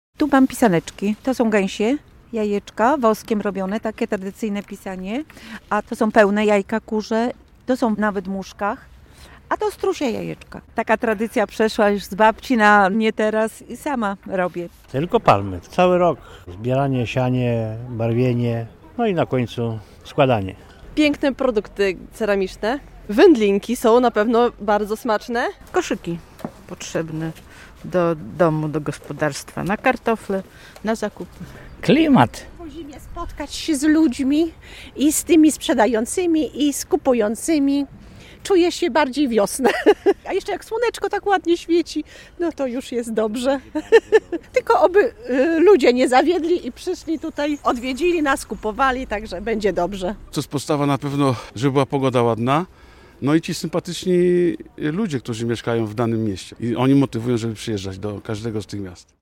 Jarmark Kaziukowy w Białymstoku - relacja